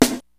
Tm8_Snare45.wav